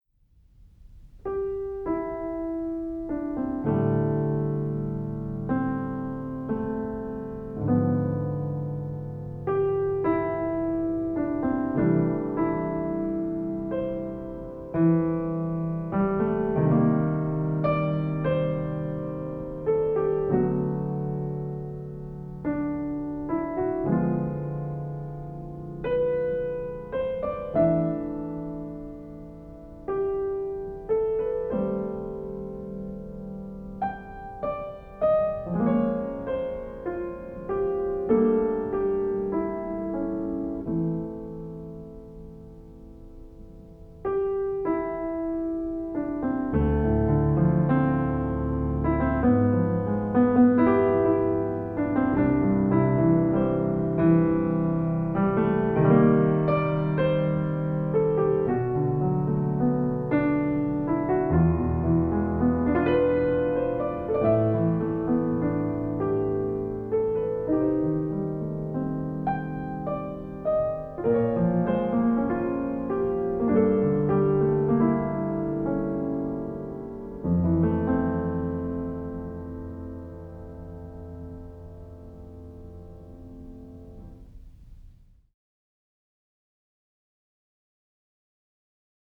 Music from the original motion picture soundtrack
Recorded at Stair 7 Studios
drums, percussion
trumpet
trombones